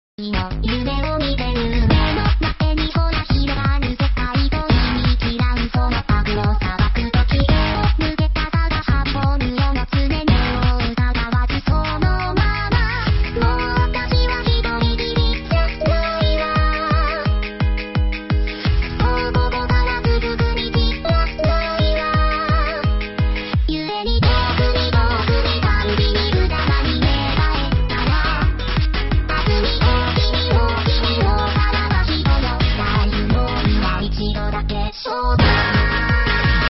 涉及术力口本家和泛术力口歌姬